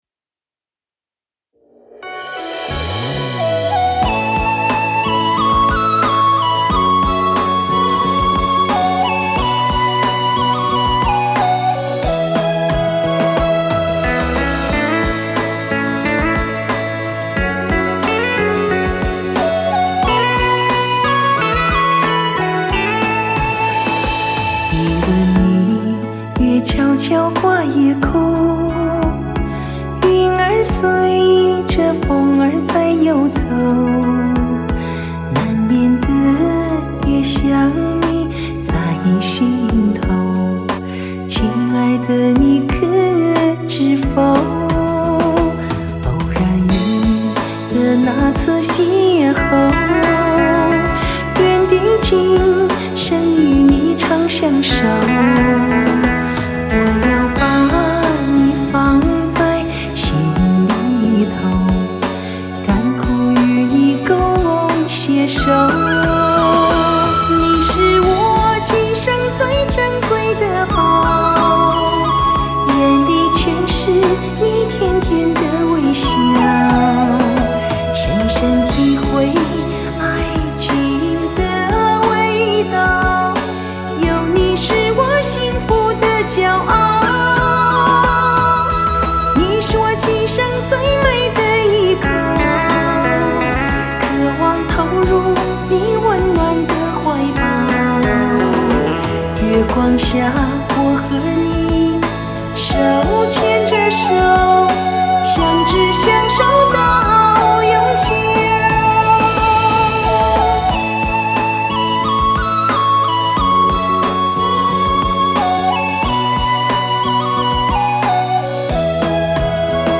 她的音色柔和甜美